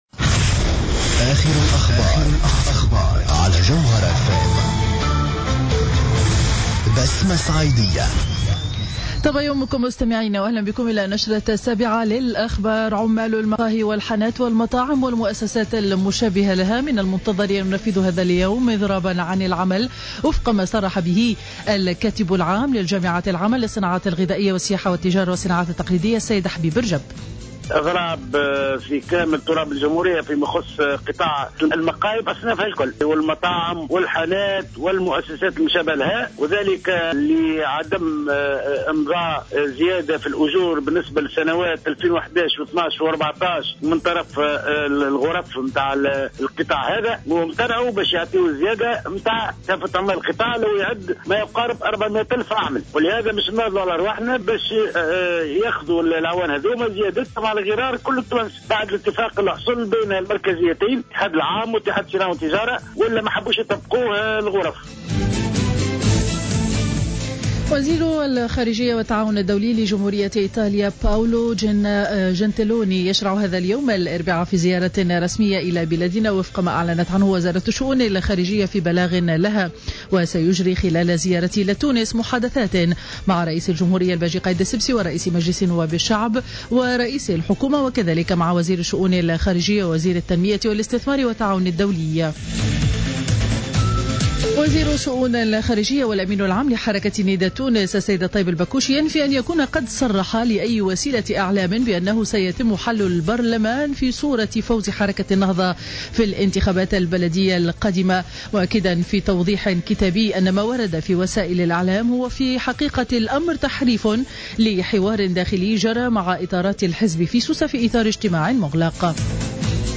نشرة أخبار السابعة صباحا ليوم الاربعاء 25 فيفري 2015